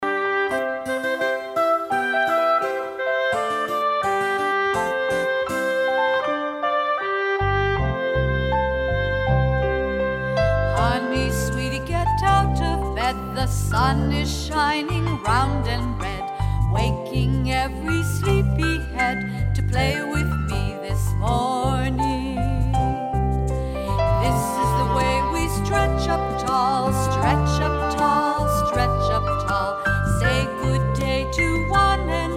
Traditional Song for Babies and Toddlers